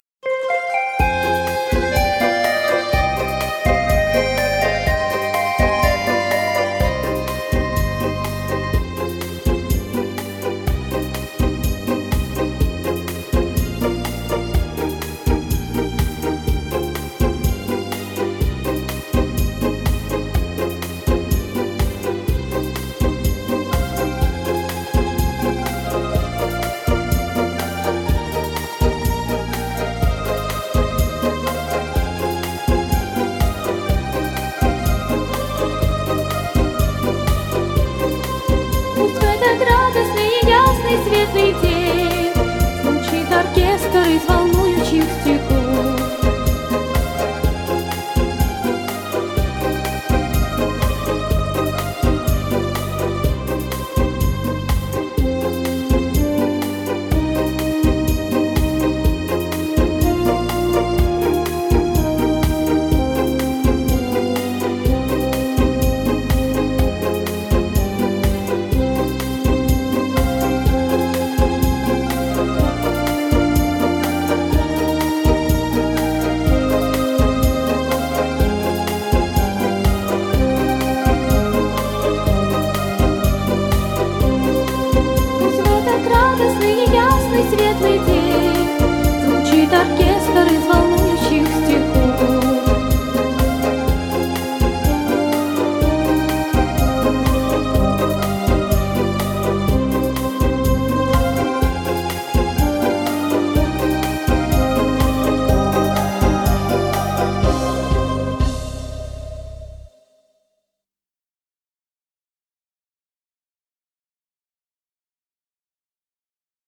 Песни про школу